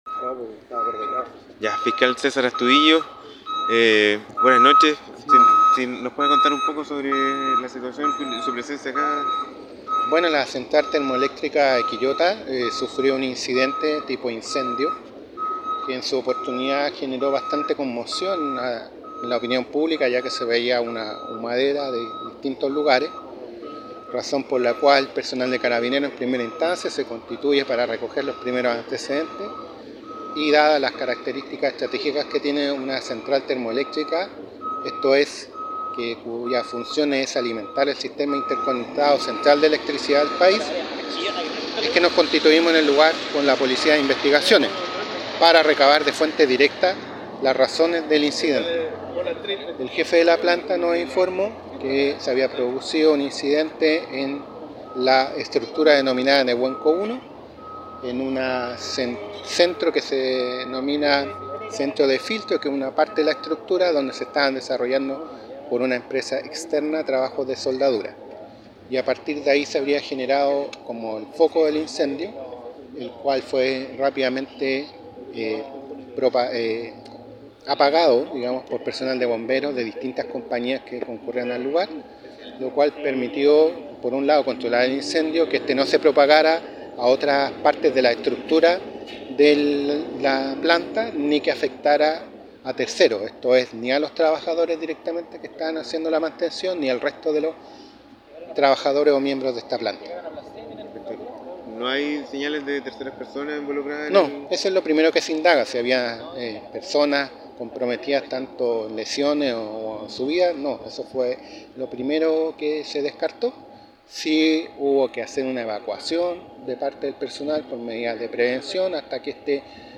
También entregó detalles de este incendio el Fiscal Jefe de Quillota, César Astudillo:
Fiscal-Jefe-de-Quillota_-Cesar-Astudillo.mp3